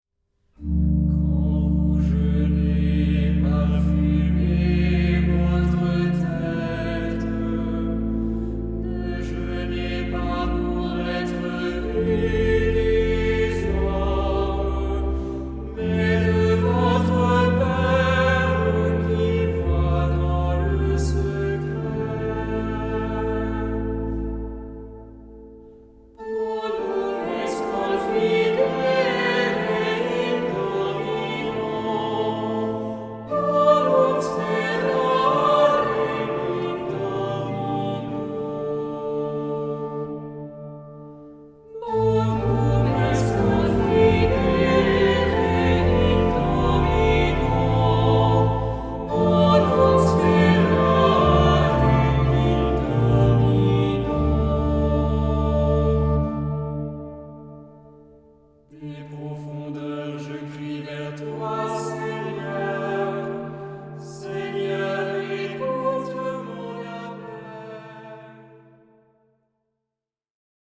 Género/Estilo/Forma: tropario ; Salmodia ; Sagrado
Carácter de la pieza : con recogimiento
Tipo de formación coral: SATB  (4 voces Coro mixto )
Instrumentos: Organo (1)
Tonalidad : re menor